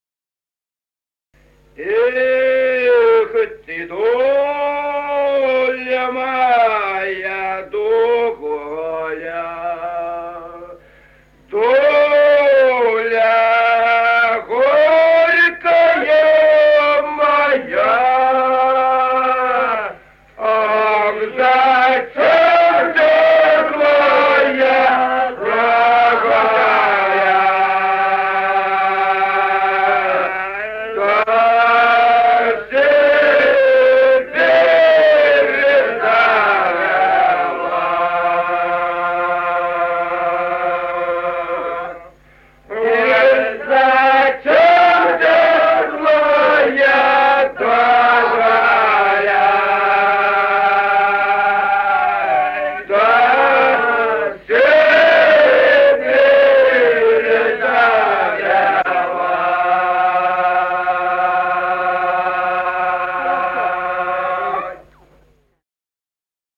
Песни села Остроглядово. Эх, ты доля И 0442-07